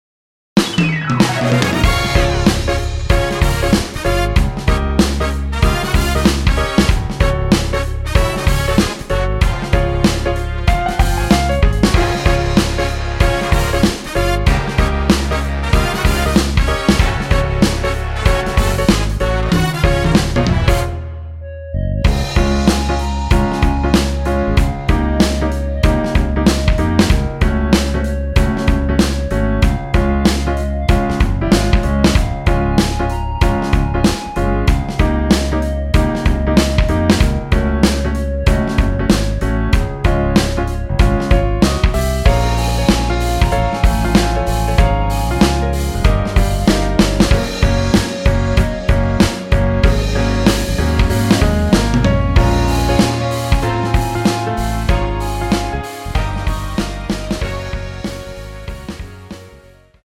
원곡보다 짧은 MR입니다.(아래 재생시간 확인)
원키에서(-1)내린 (1절앞+후렴)으로 진행되는 멜로디 포함된 MR입니다.
Db
앞부분30초, 뒷부분30초씩 편집해서 올려 드리고 있습니다.